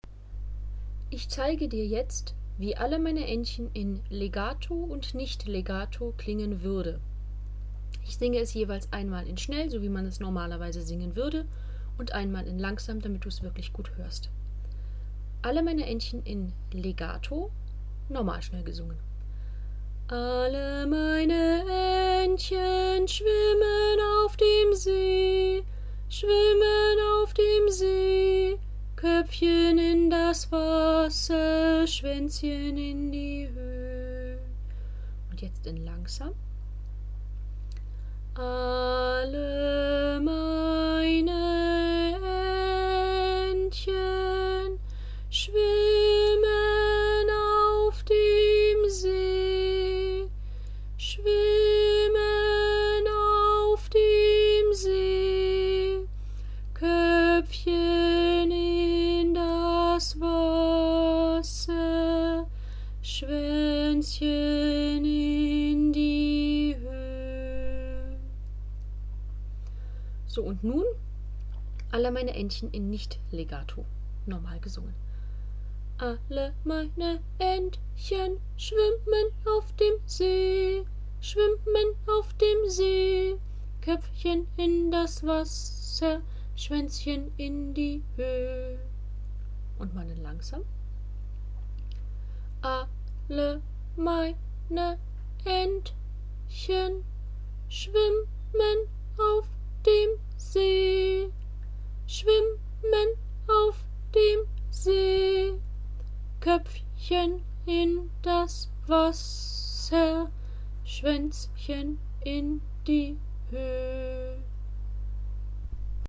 Am Beispiel von „Alle meine Entchen“ zeige ich dir, wie es klingen soll (gebunden) und wie es nicht klingen soll (nicht gebunden):
Du hörst sehr deutlich, dass ich die Dauer der Töne verändert habe und dadurch auch die Verbindung zwischen den Wörtern.
Vielleicht ist dir auch aufgefallen, dass es mir beim Nicht-Legato-Singen, das mehr Sprechsingen als wirkliches Singen war, schwerer fiel, die exakten Tonhöhen zu treffen. Das Lied klang dadurch angestrengter und manche Töne waren nicht astrein.
Sofort-besser-klingen-Legato-und-Nicht-Legato.mp3